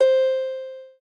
lyre_c1.ogg